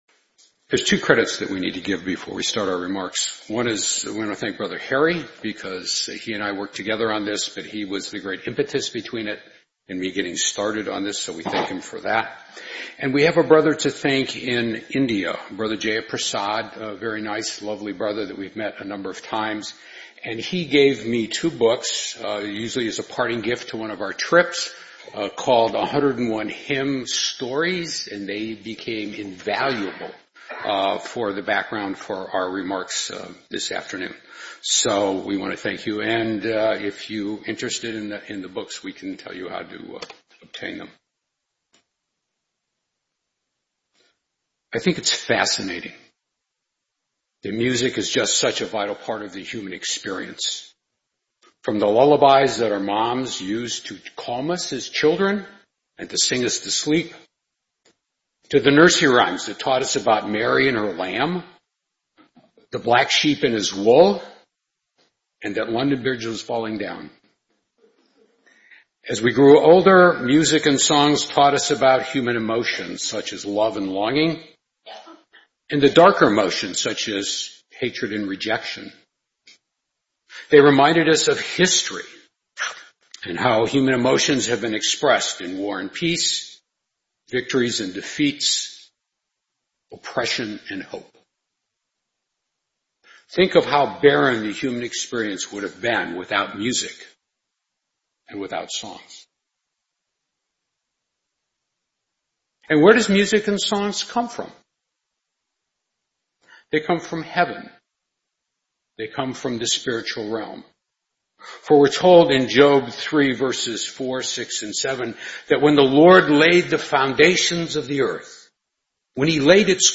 Series: 2025 Chicago Memorial Day Convention